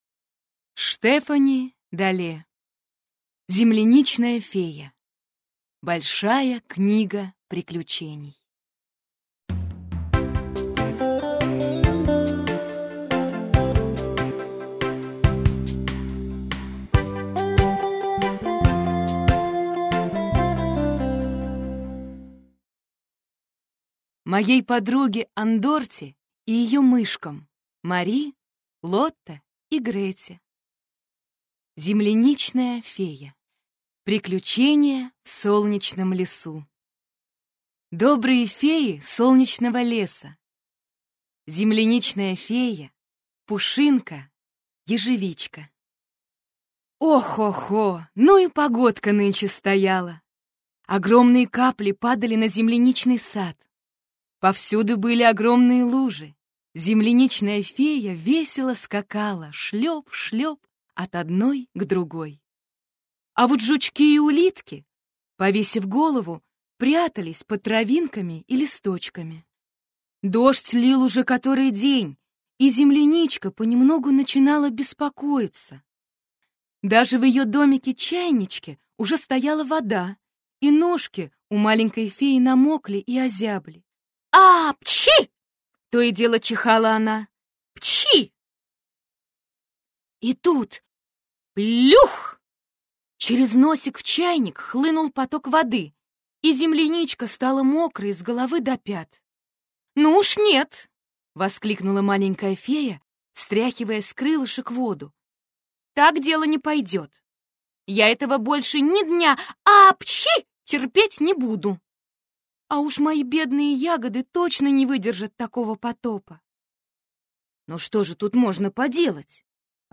Аудиокнига Земляничная фея. Большая книга приключений | Библиотека аудиокниг